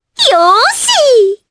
Requina-Vox_Happy4_jp.wav